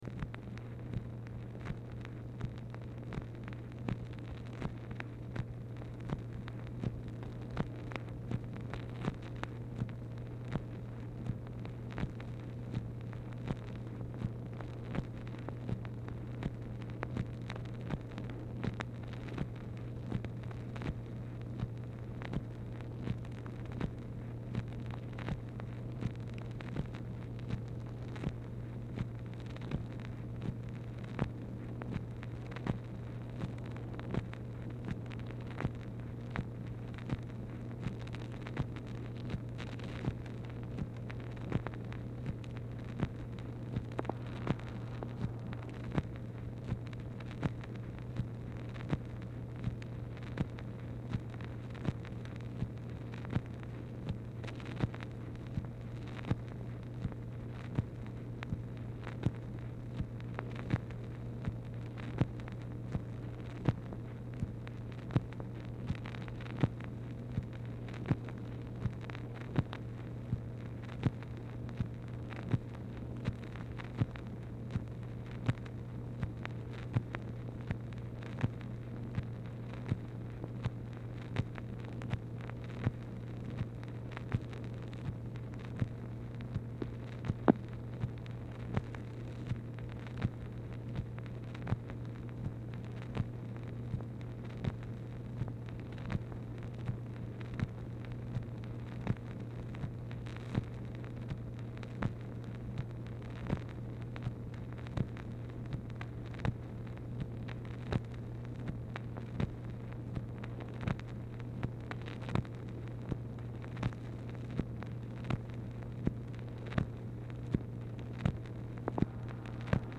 Telephone conversation # 3137, sound recording, MACHINE NOISE, 4/26/1964, time unknown | Discover LBJ
Format Dictation belt
White House Telephone Recordings and Transcripts Speaker 2 MACHINE NOISE